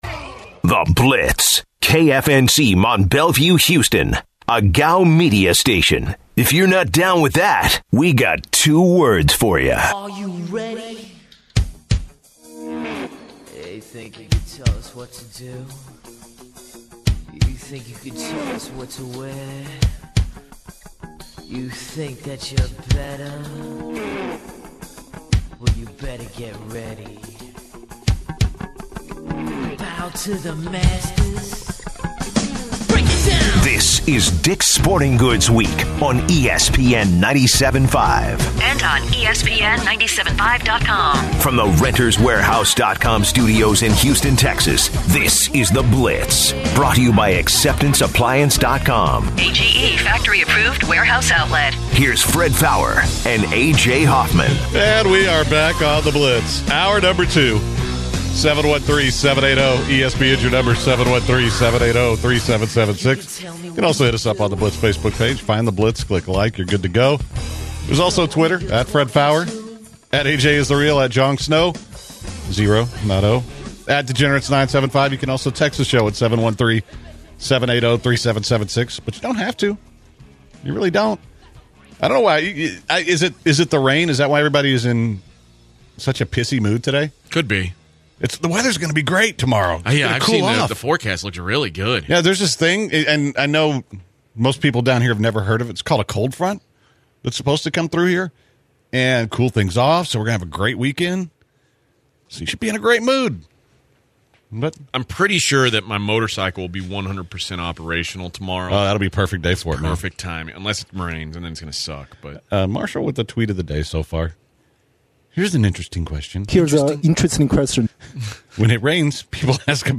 Finally, they interviewed Giants kicker, Josh Brown, in a hilarious interview.